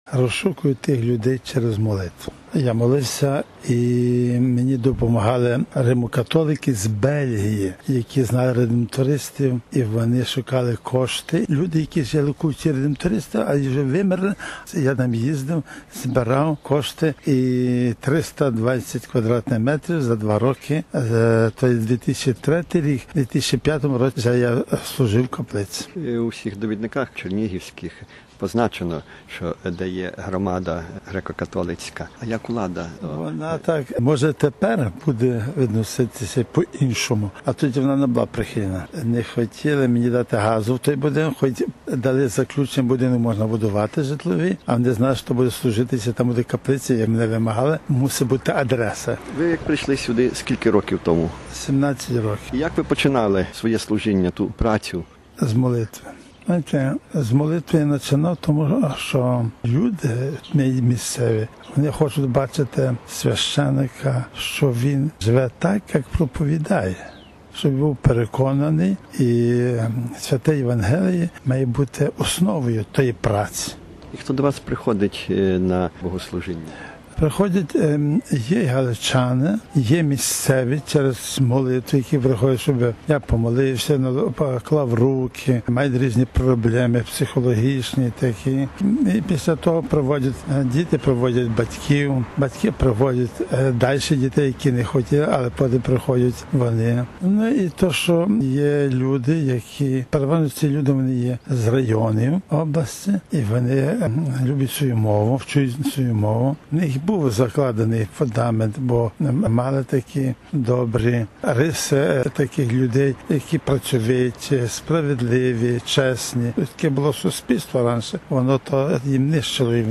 В інтерв’ю